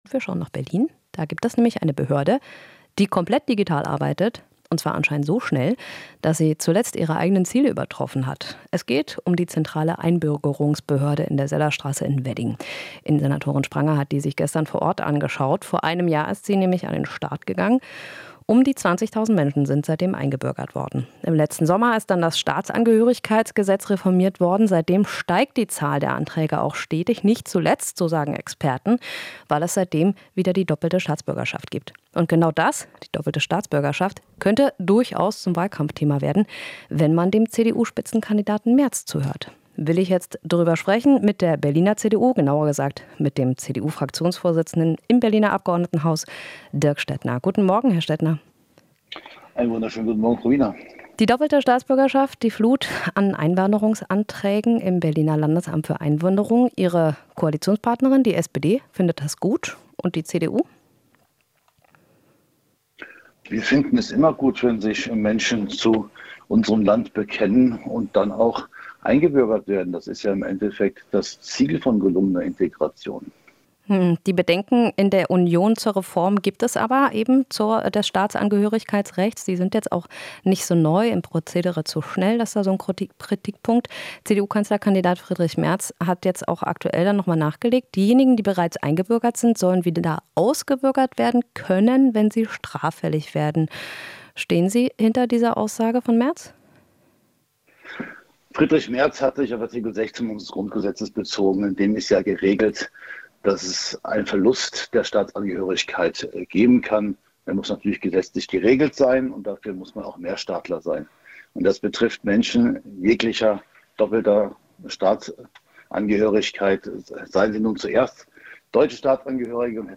Interview - Einbürgerungsrecht: Stettner (CDU) verteidigt Vorstoß von Merz